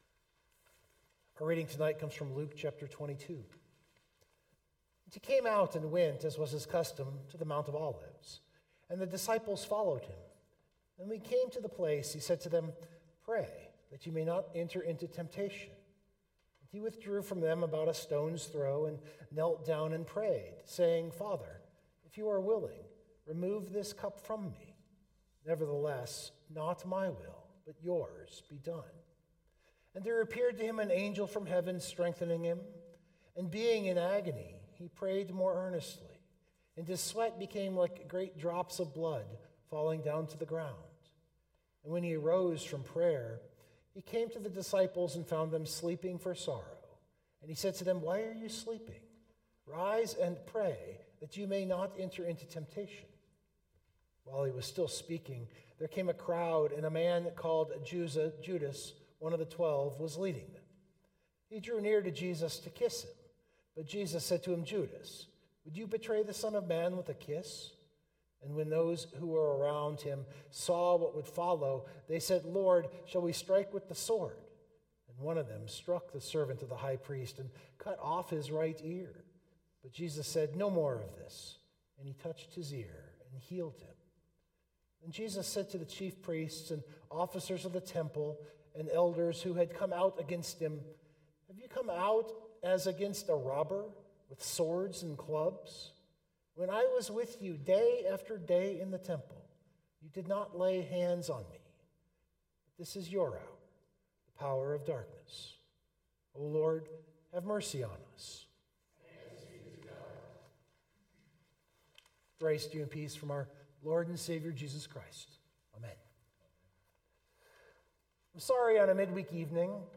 For a midweek meditation, it is probably a little too serious.